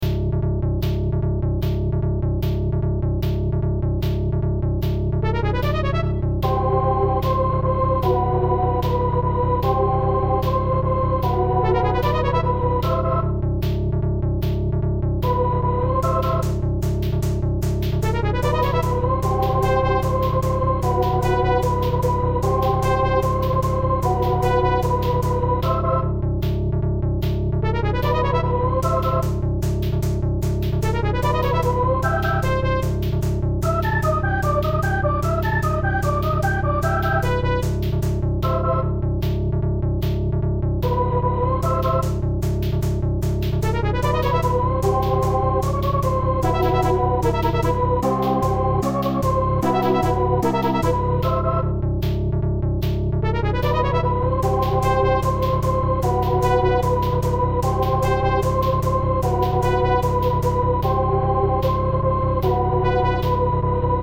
MIDI battle theme
Loops.